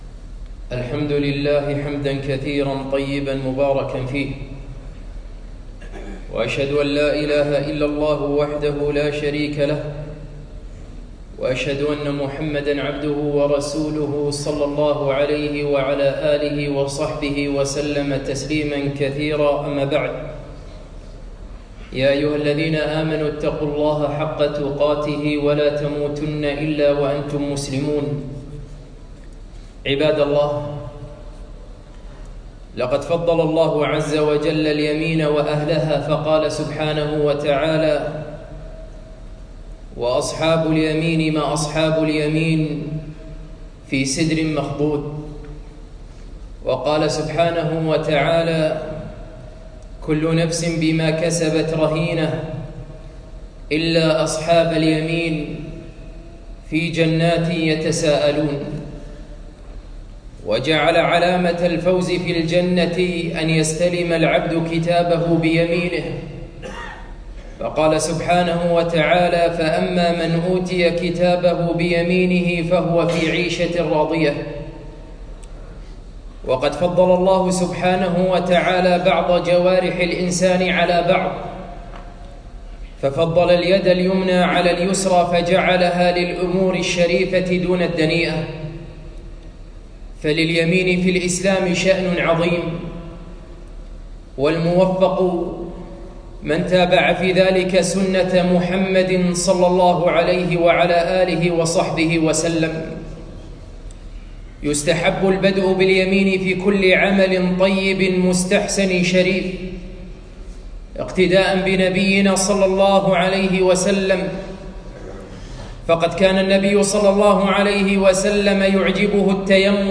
خطبة - كان يعجبه التيمن